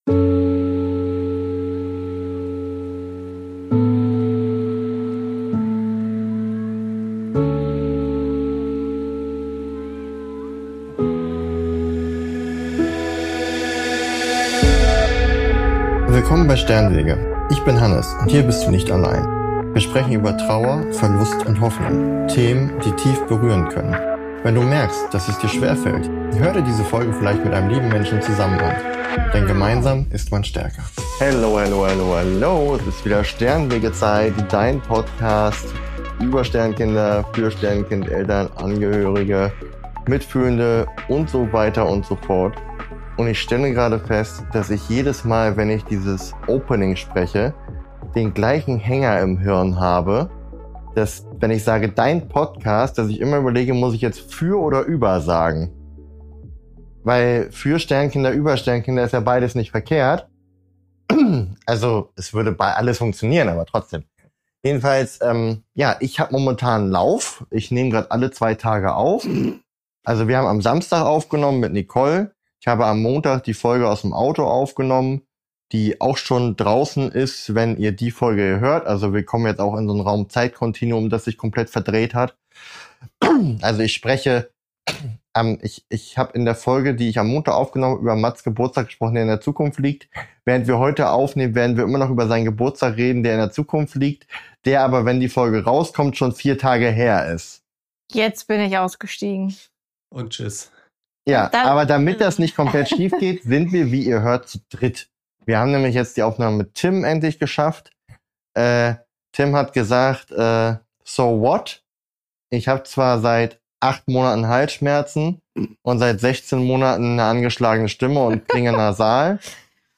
Zwischendurch wird es – typisch Sternenwege – persönlich, ehrlich und auch mal humorvoll.